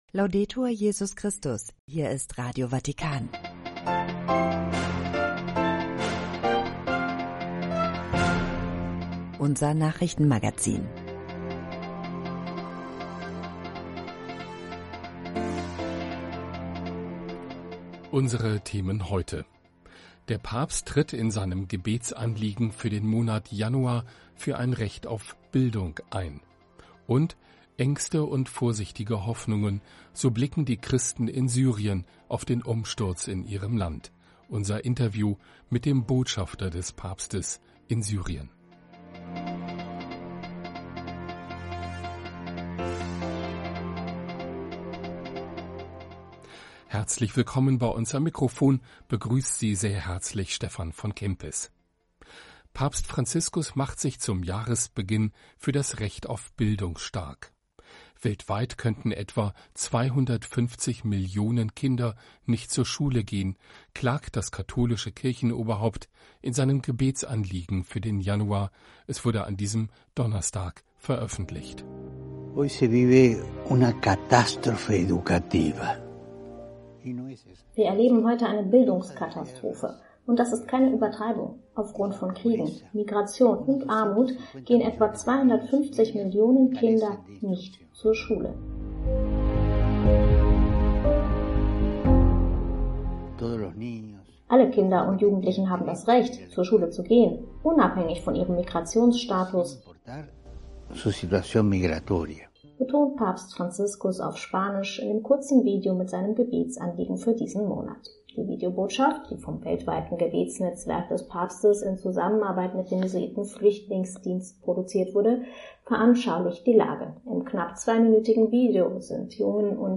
Treffpunkt Weltkirche - Nachrichtenmagazin (18 Uhr).